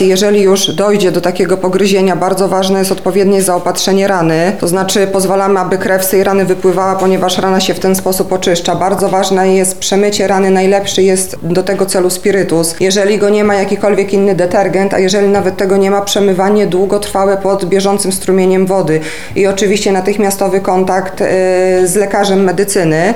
– tłumaczy Agnieszka Smyl, Lubelski Wojewódzki Lekarz Weterynarii